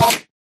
Sound / Minecraft / mob / endermen / hit3.ogg